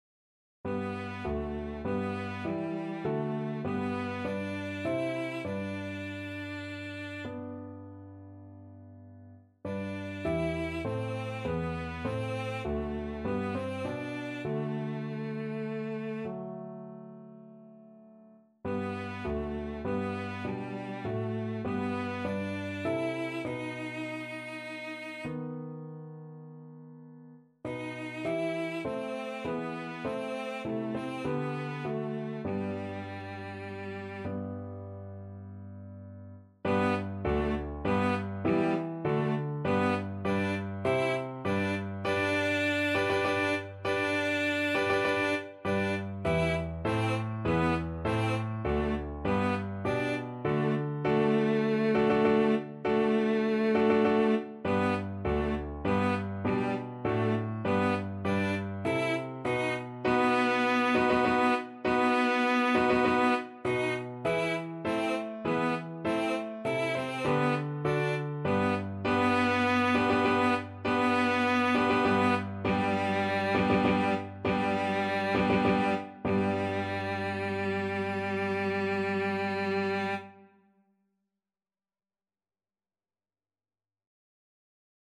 Classical Saint-Saëns, Camille Chorale from Third Organ Symphony Finale Cello version
Cello
9/4 (View more 9/4 Music)
Maestoso
G major (Sounding Pitch) (View more G major Music for Cello )
Classical (View more Classical Cello Music)